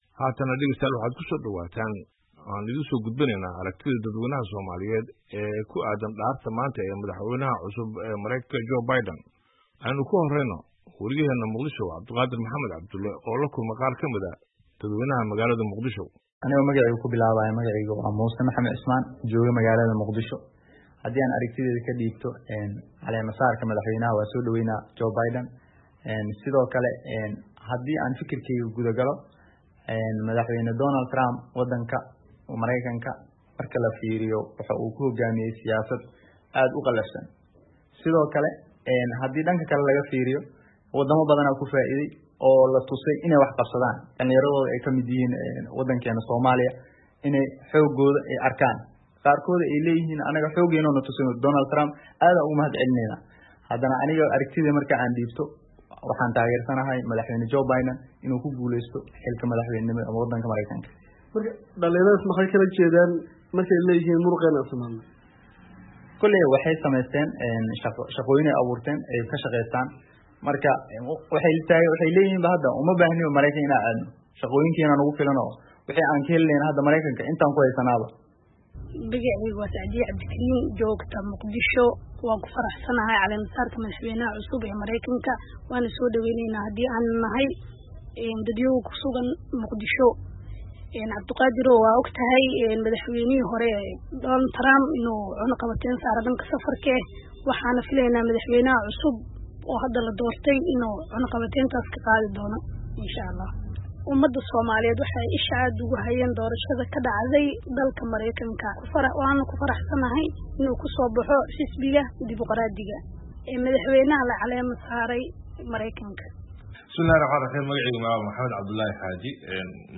Haddaba sida Soomaaliya looga arko dhaarinta Biden, ayaa waxaa warbixinadan kasoo diray weriyayaasha VOA.